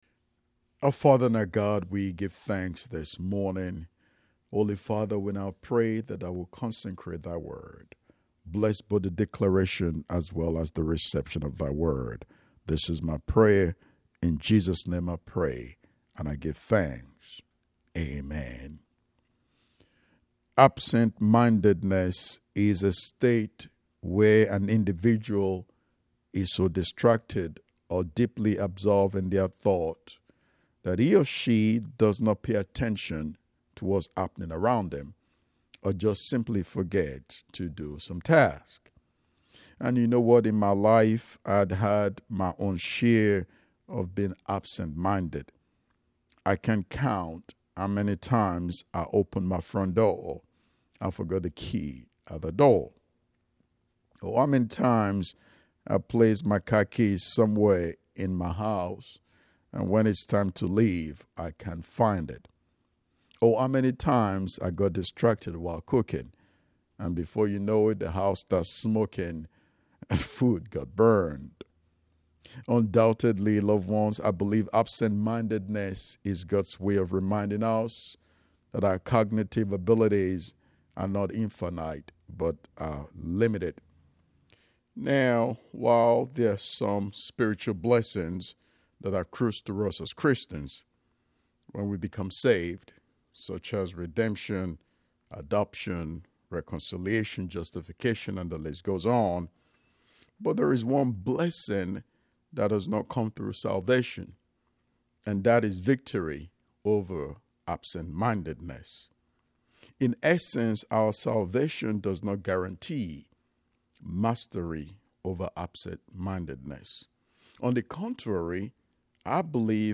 10:30 AM Service
10:30 AM Service It’s Not Too Late To Start Click to listen to the sermon. https